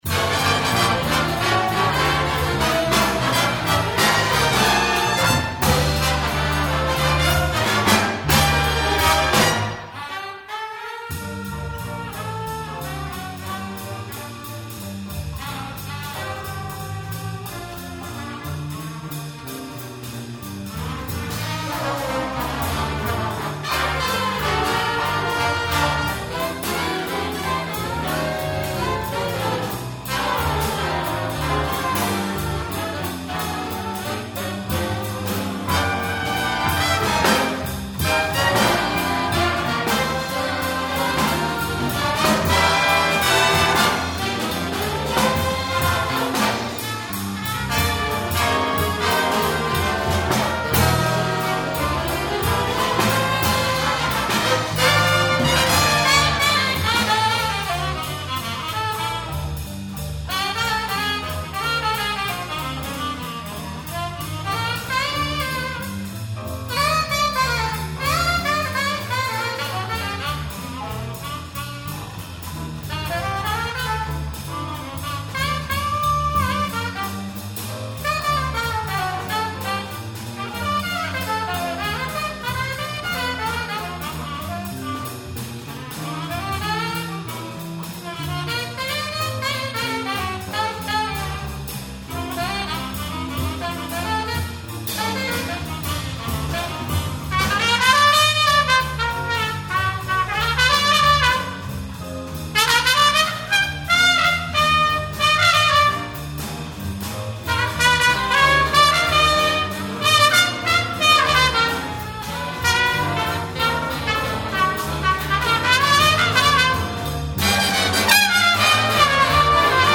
2008-02-03 田原本タワラモト弥生ヤヨイサトホール ギンオンじゃず楽団ガクダン Birdland
(結成ケッセイ40周年シュウネン記念キネンコンサート) Orange Sherbet